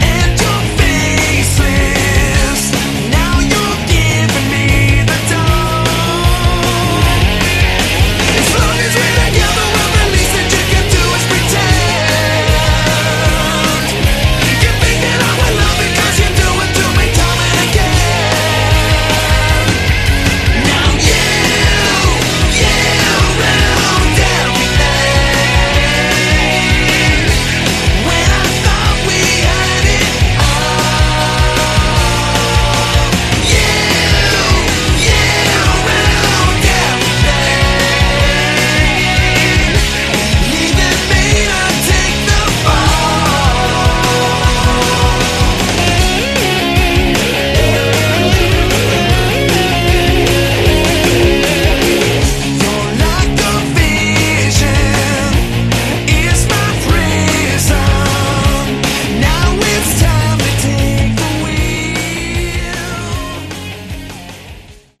Category: Hard Rock
lead vocals, keys
guitars, vocals, keys
bass, vocals
drums, vocals